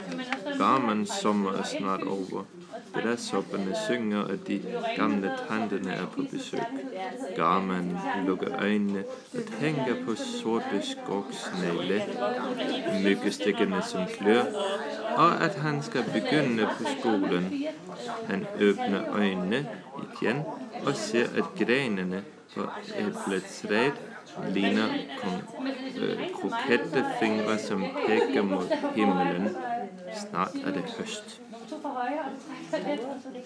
Garmanns sommer med dansk accent